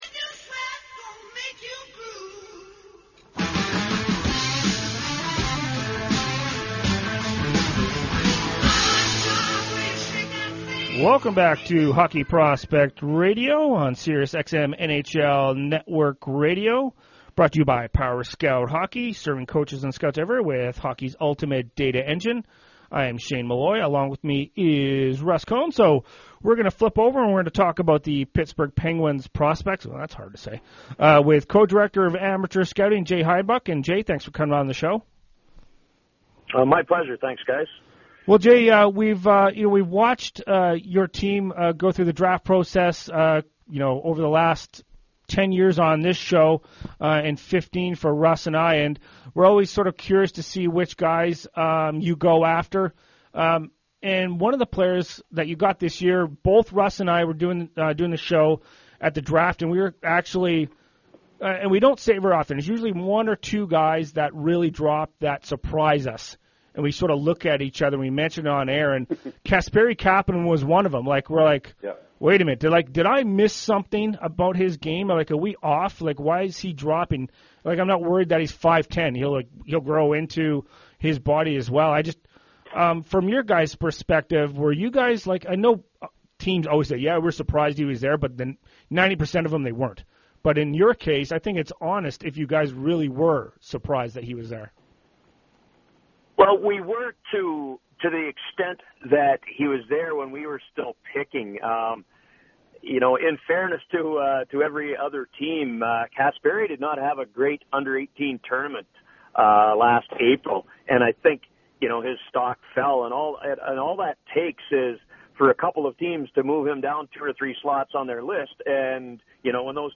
On the Hockey Prospects radio show on NHL Network radio